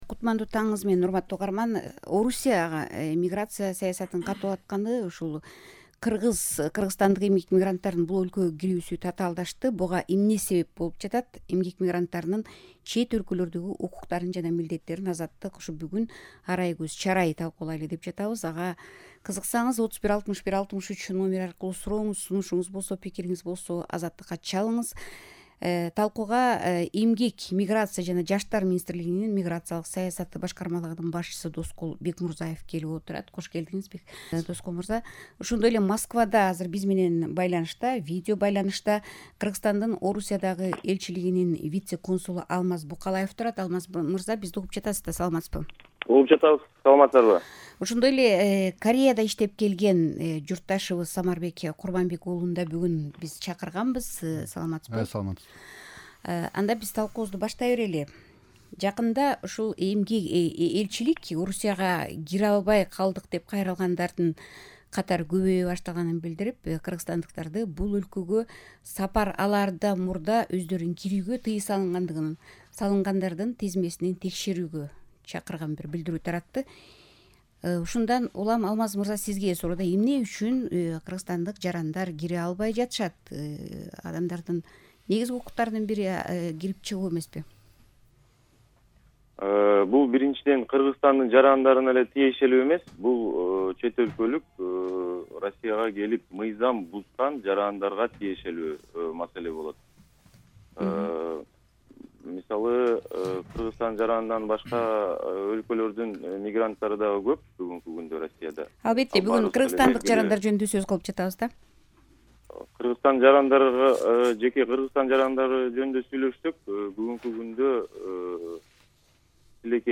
Эмгек мигранттарынын чет өлкөлөрдөгү укуктары жана милдеттерин “Азаттык” бүгүн түз ободо “арай көз чарай” талкуулайт.